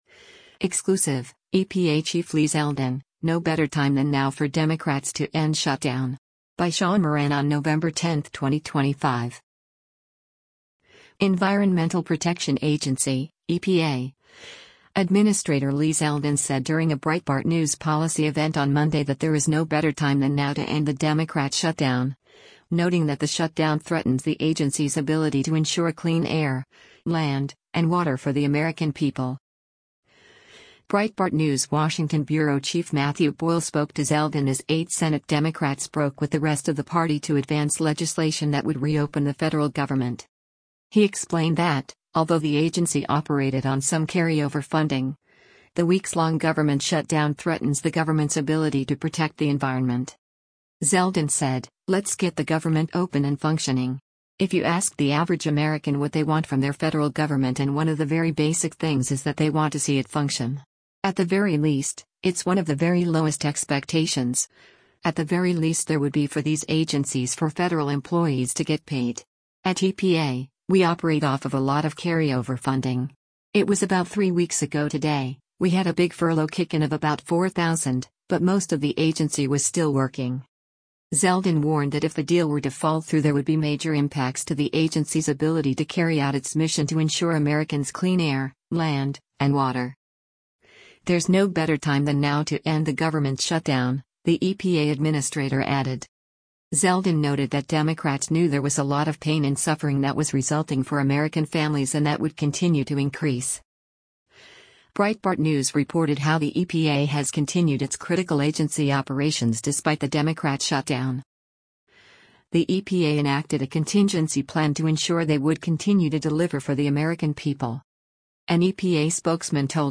Environmental Protection Agency (EPA) Administrator Lee Zeldin said during a Breitbart News policy event on Monday that there is “no better time than now” to end the Democrat shutdown, noting that the shutdown threatens the agency’s ability to ensure clean air, land, and water for the American people.
Environmental Protection Agency Administrator Lee Zeldin speaks during a Breitbart News policy event on Monday, November 10, 2025, in Washington, DC.